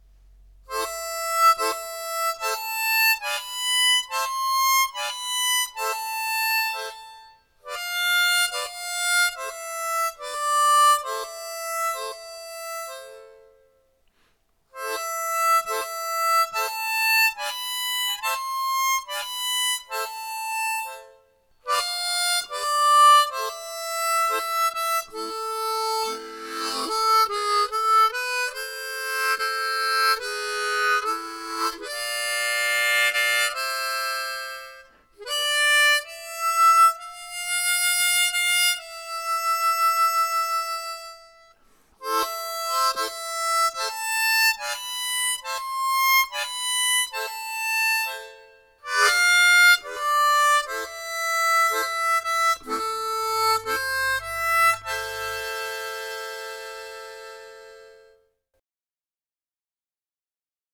短音階（マイナースケール）を使用したものを、マイナーチューニングと呼びます。
和声的短音階（ハーモニックマイナースケール）